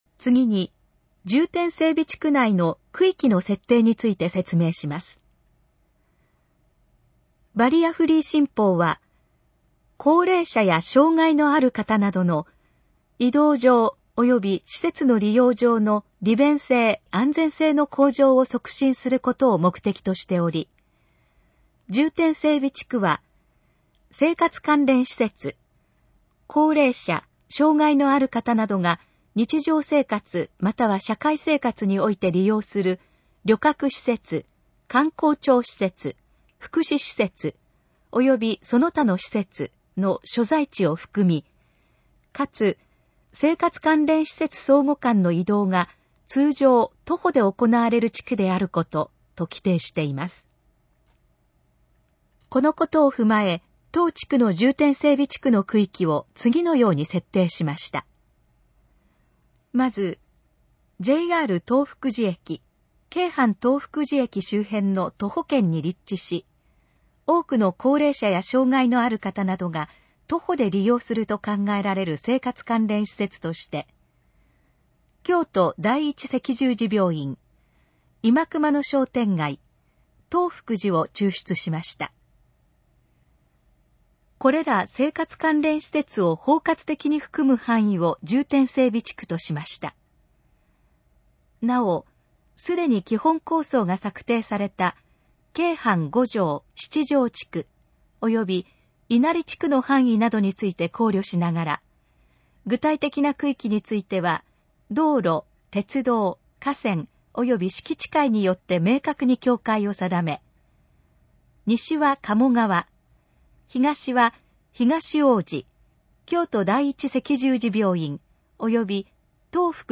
このページの要約を音声で読み上げます。
ナレーション再生 約546KB